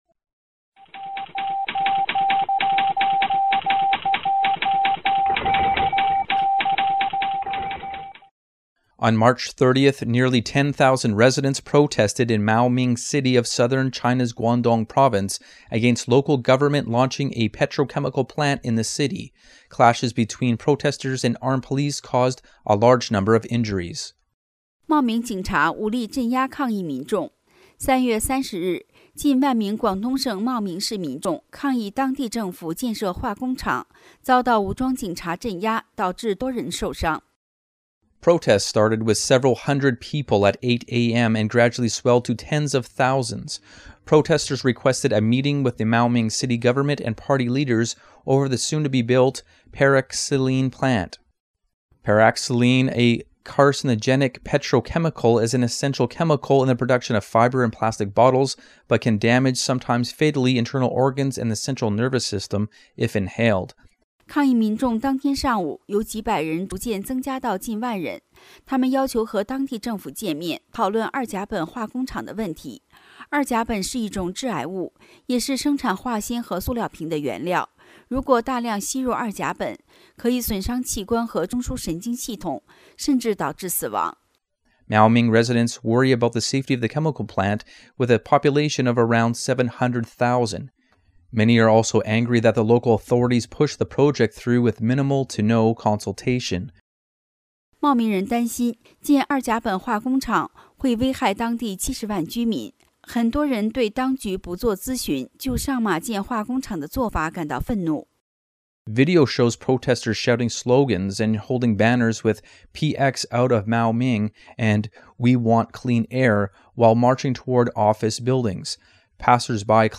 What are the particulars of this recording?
128kbps Mono